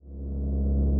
engine-in.ogg